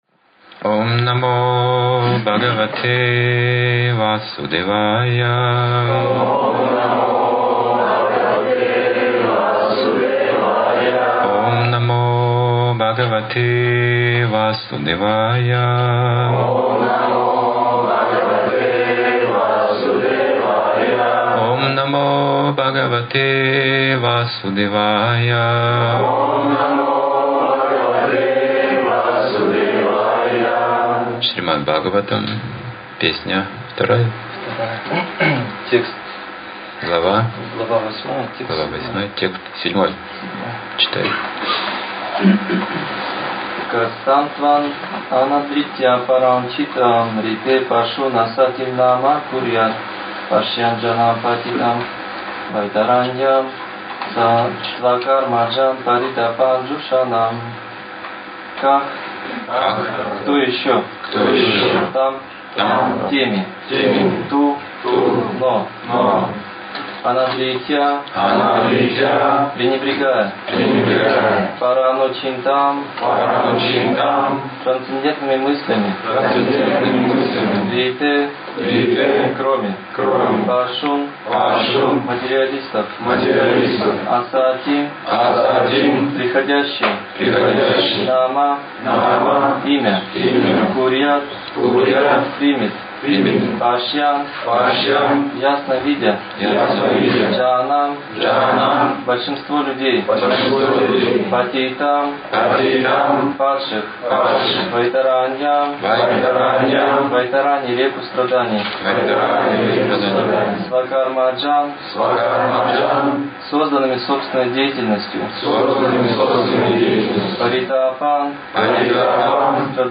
Темы, затронутые в лекции: Почему человек отказывается идти путем самоосознания?
(11.05.2011, Алматы)